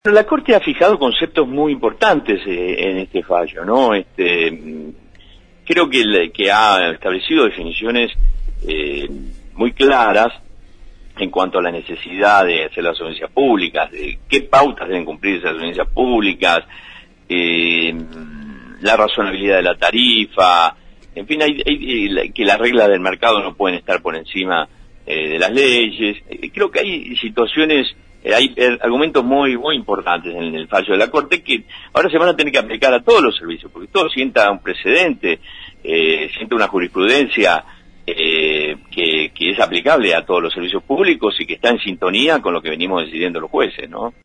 Conversamos con Arias sobre esto.